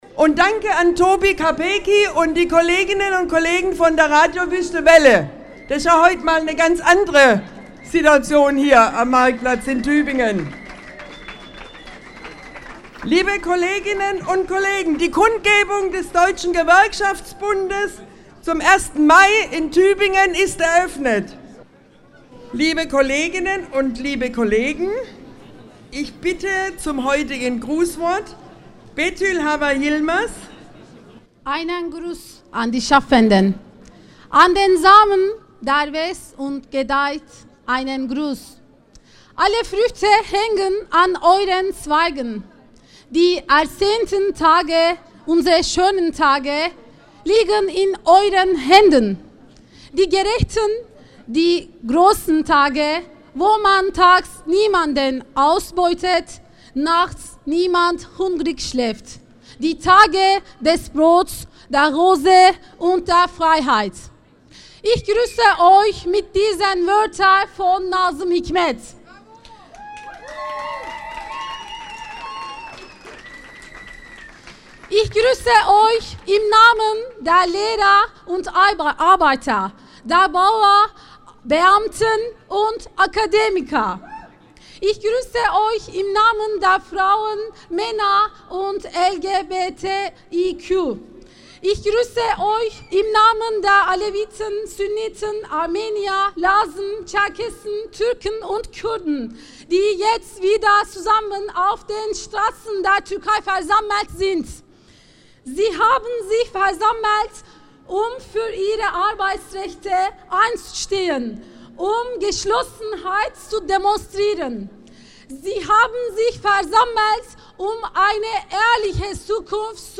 Das Grußwort 2017